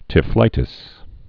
(tĭf-lītĭs)